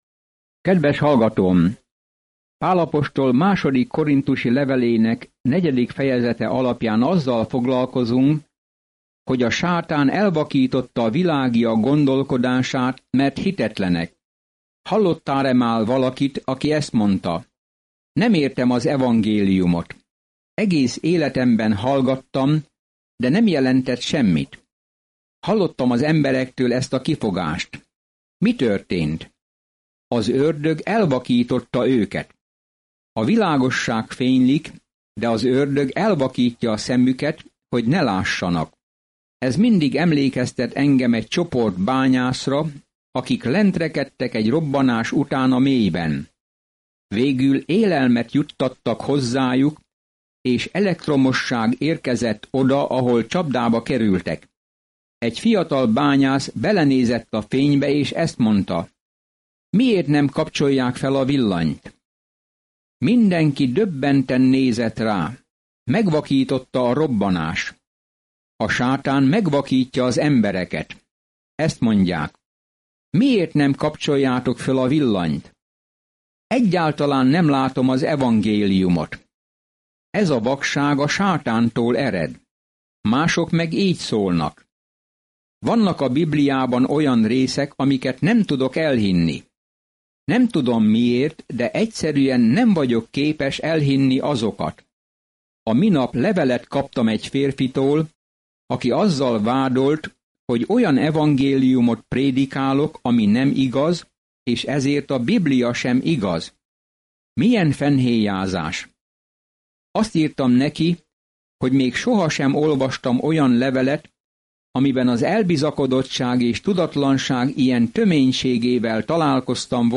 Szentírás 2Korinthus 4:5-16 Nap 5 Olvasóterv elkezdése Nap 7 A tervről A Krisztus testén belüli kapcsolatok örömeit emeli ki a korinthusiakhoz írt második levél, miközben hallgatod a hangos tanulmányt, és olvasol válogatott verseket Isten szavából.